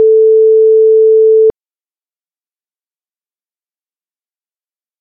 Replace North American ringback/busy tones with French standard
(440 Hz single tone with 1.5s/3.5s and 0.5s/0.5s cadences).
phone-outgoing-calling.oga